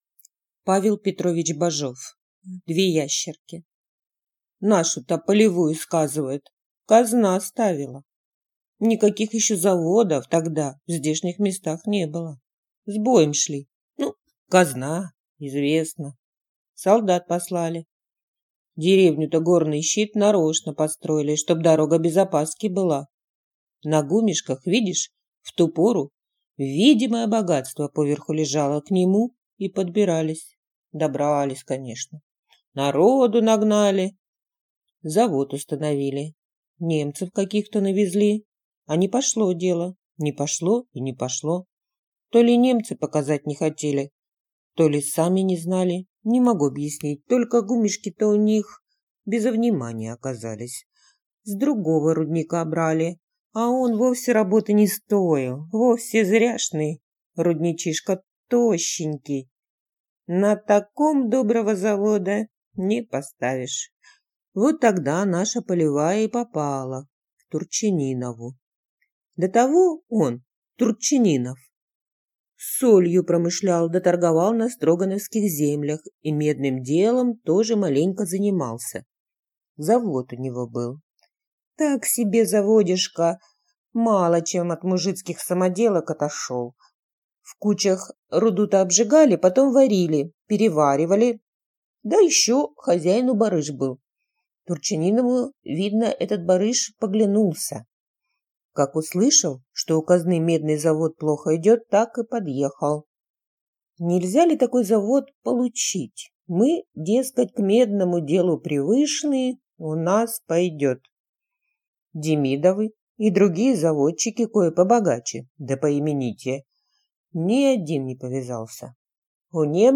Аудиокнига Две ящерки | Библиотека аудиокниг